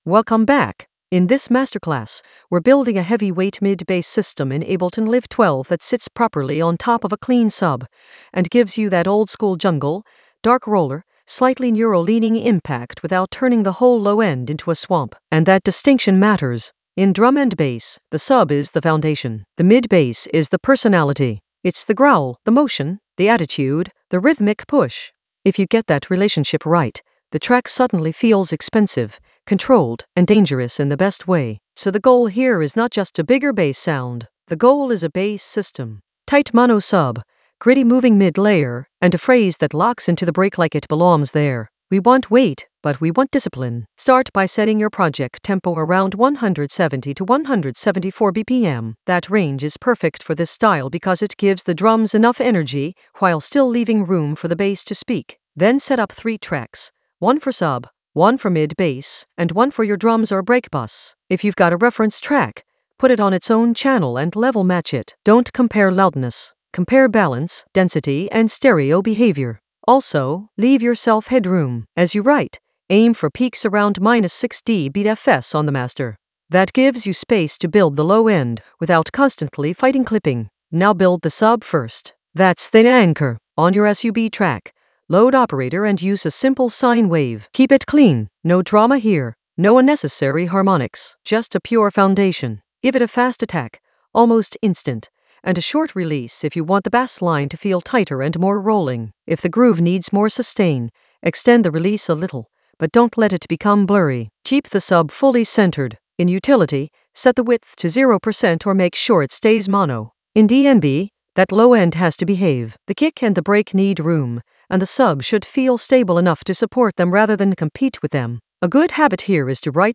Narrated lesson audio
The voice track includes the tutorial plus extra teacher commentary.
An AI-generated advanced Ableton lesson focused on Masterclass for mid bass for heavyweight sub impact in Ableton Live 12 for jungle oldskool DnB vibes in the Basslines area of drum and bass production.